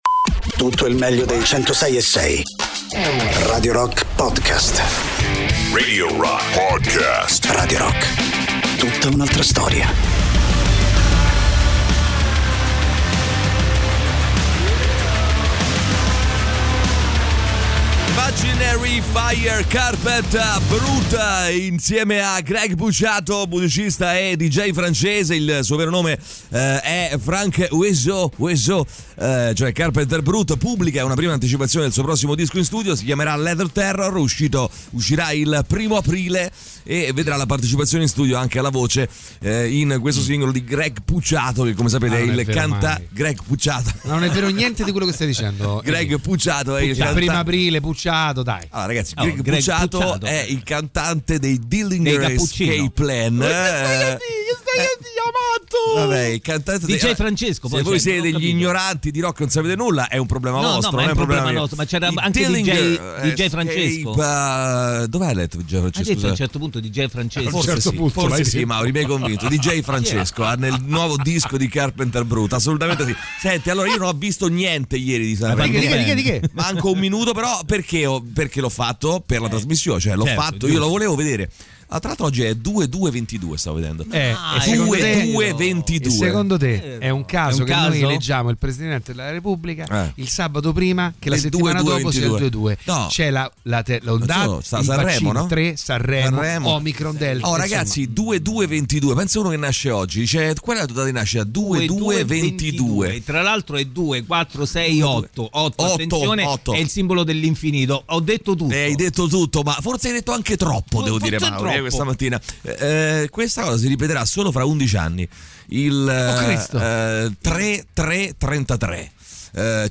in diretta dal lunedì al venerdì dalle 6 alle 10 sui 106.6 di Radio Rock.